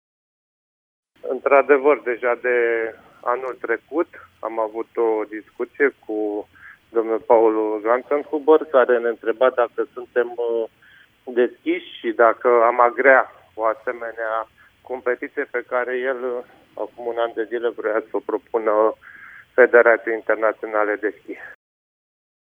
Primarul stațiunii Râșnov, Liviu Butnariu: